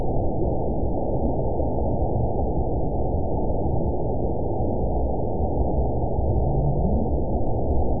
event 922027 date 12/25/24 time 20:19:22 GMT (11 months, 1 week ago) score 8.09 location TSS-AB02 detected by nrw target species NRW annotations +NRW Spectrogram: Frequency (kHz) vs. Time (s) audio not available .wav